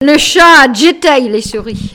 locutions vernaculaires
Catégorie Locution